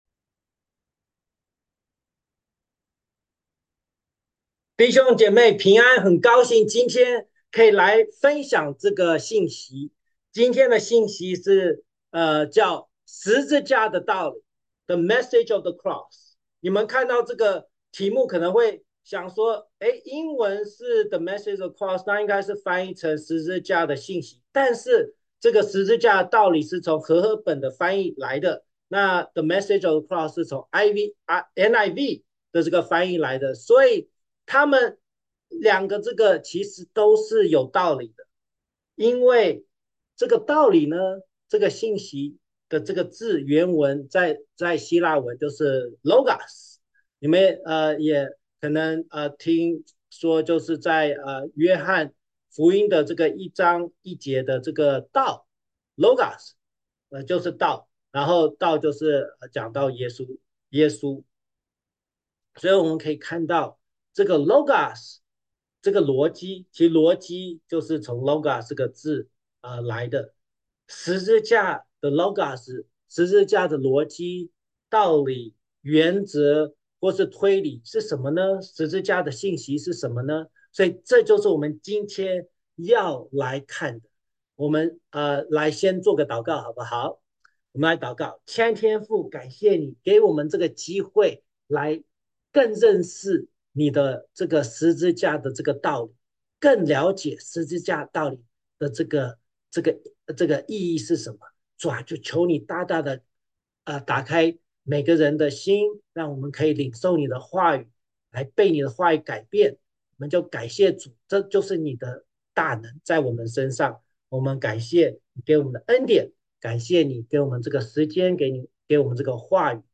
主日讲道